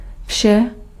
Ääntäminen
IPA: /vʃɛ/